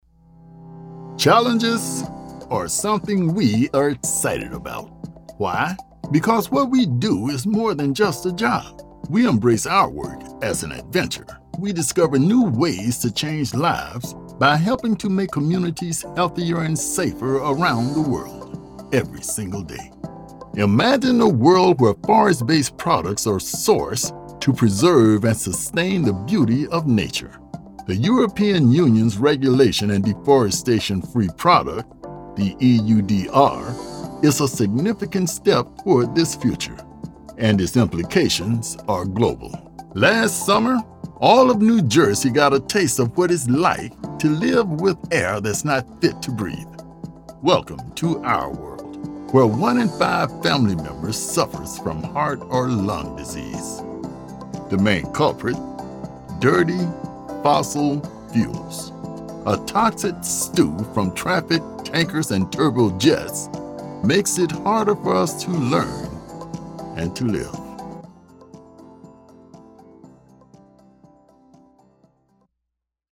Male
Adult (30-50), Older Sound (50+)
Narration
Casual Upbeat
0316NARRATION_DEMO-CONVERSATIONAL_READS_DEMO--CD.mp3